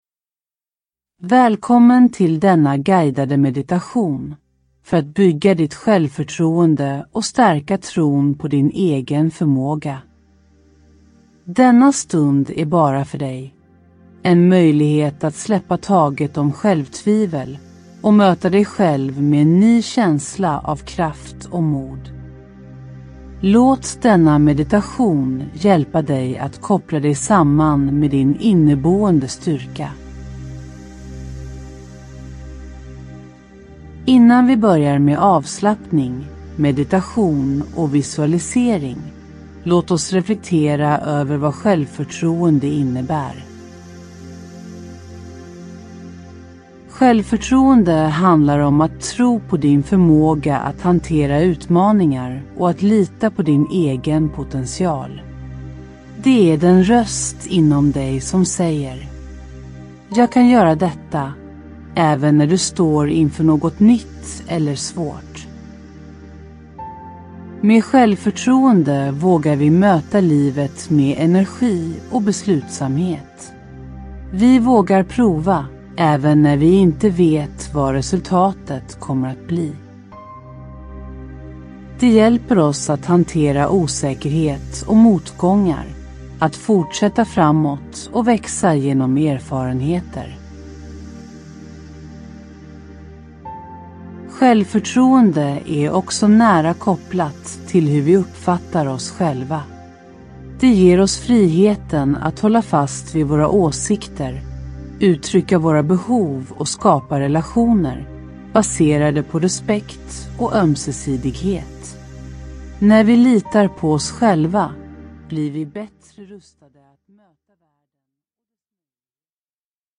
Ljudbok
Denna guidade meditation hjälper dig att möta dina rädslor, omfamna dina styrkor och bygga ett stabilt självförtroende som bär dig genom livets alla utmaningar.